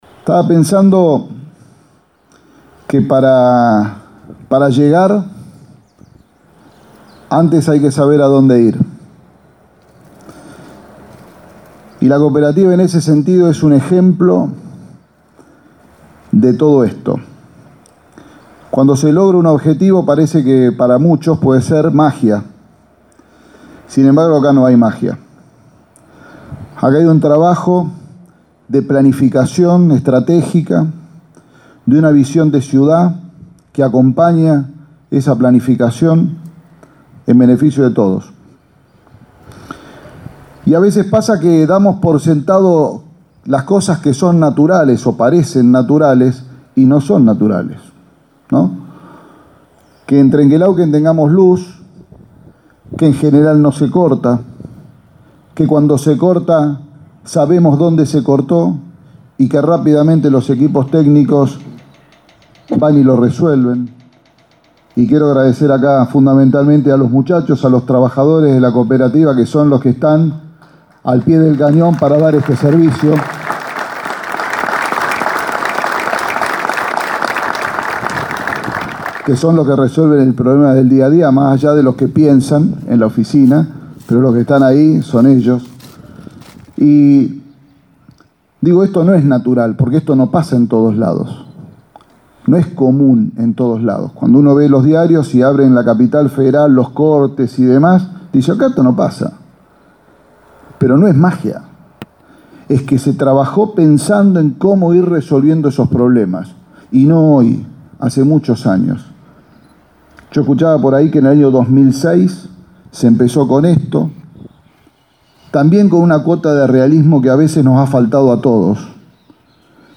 El Intendente Miguel Fernández habló en la inauguración del Centro de Distribución y Maniobras de 33kv. que construyó la Cooperativa de Electricidad de Trenque Lauquen.
Miguel-Fern--ndez-inauguracion-33Kv..mp3